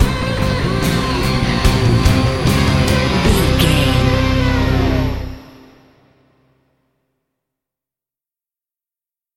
Fast paced
In-crescendo
Thriller
Aeolian/Minor
synthesiser